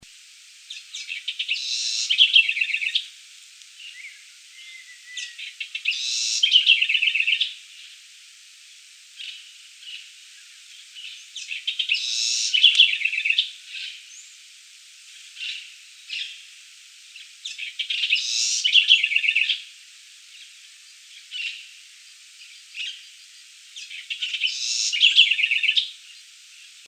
Southern House Wren (Troglodytes musculus)
Life Stage: Adult
Location or protected area: Parque Nacional Río Pilcomayo
Condition: Wild
Certainty: Observed, Recorded vocal
Ratona-Comun.MP3